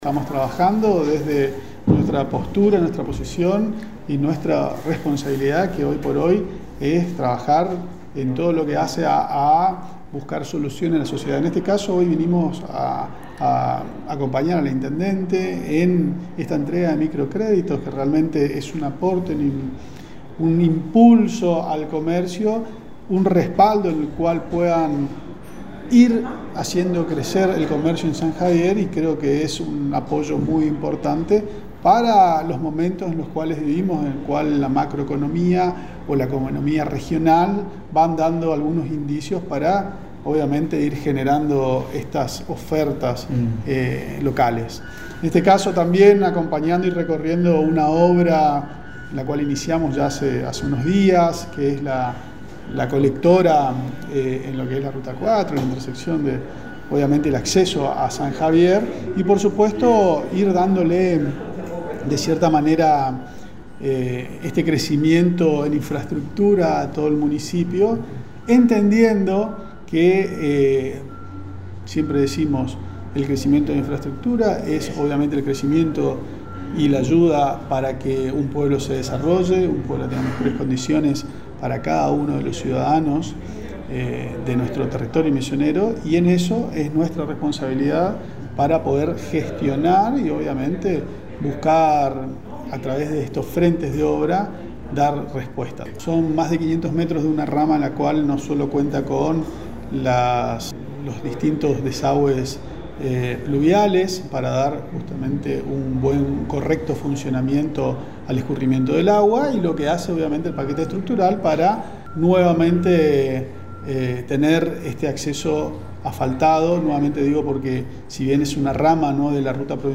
En una reciente entrevista con FM Alto Uruguay, el presidente de Vialidad de Misiones, Ingeniero Sebastián Macías, compartió detalles de su visita a la localidad de San Javier, donde se llevó a cabo la entrega de microcréditos en conjunto con el intendente local, Vilchez.
ING SEBASTIAN MACIAS PRESIDENTE DE VIALIDAD MISIONES